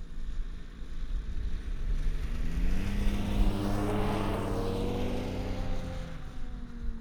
Snowmobile Description Form (PDF)
Subjective Noise Event Audio File (WAV)